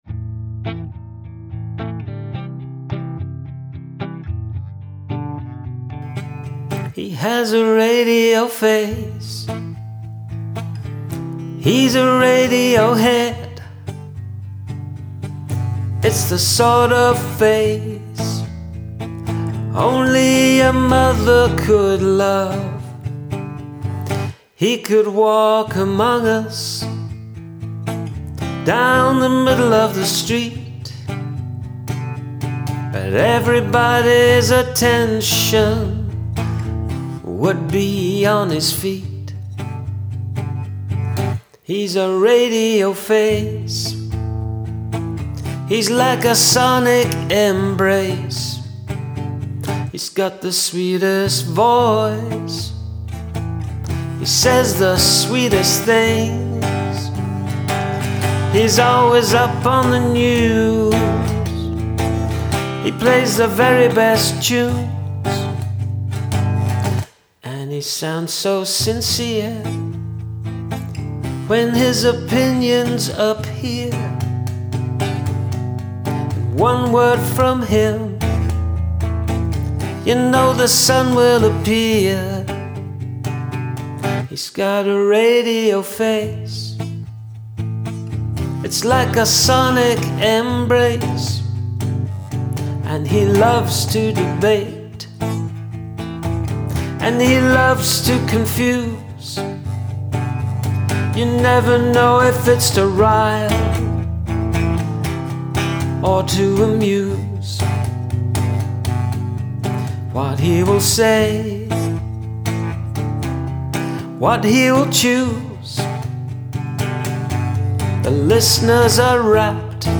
Oh I like the rhythmic change in the 2nd verse.